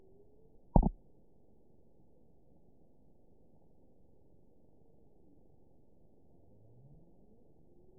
event 920923 date 04/16/24 time 04:28:15 GMT (1 week, 6 days ago) score 9.35 location TSS-AB04 detected by nrw target species NRW annotations +NRW Spectrogram: Frequency (kHz) vs. Time (s) audio not available .wav